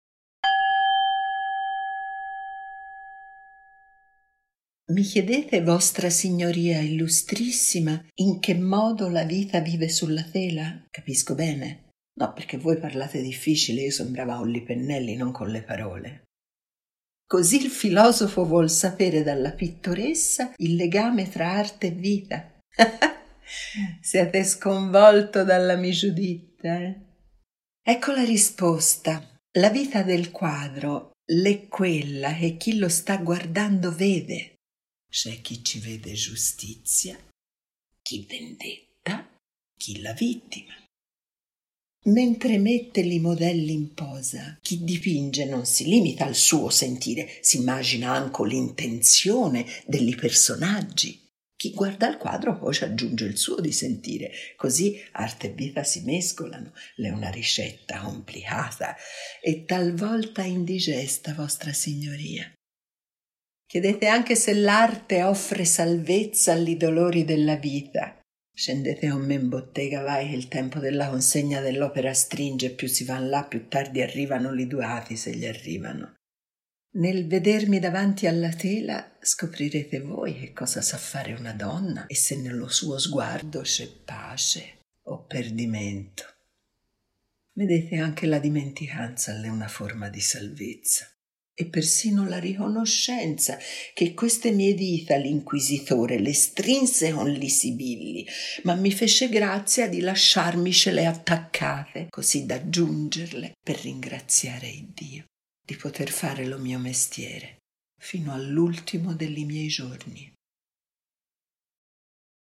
Artemisia Gentileschi (interpretata da Pamela Villoresi).